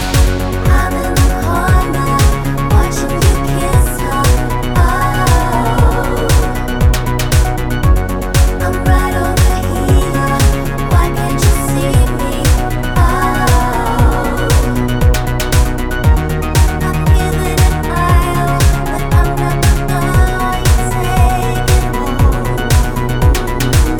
no Backing Vocals Dance 3:43 Buy £1.50